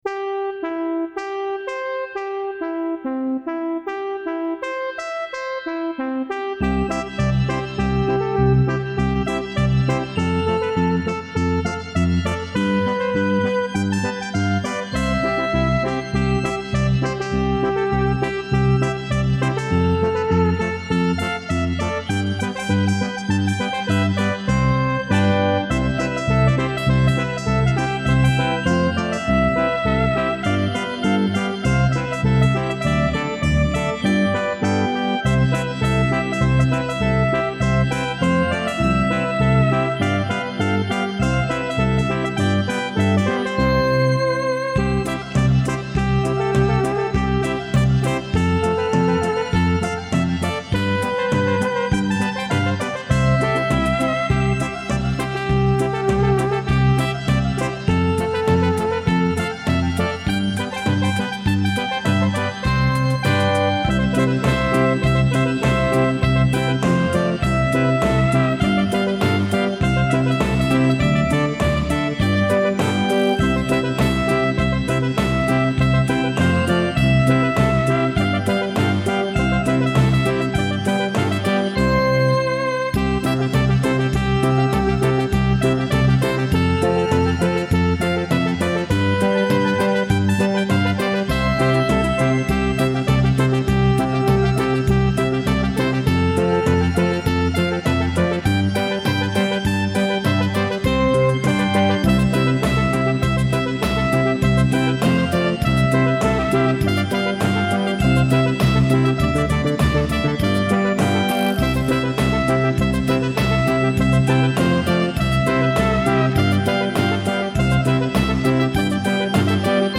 Some Christmas ditties that I created oodles of years ago on a Yamaha V50 (All tunes arranged and performed by me)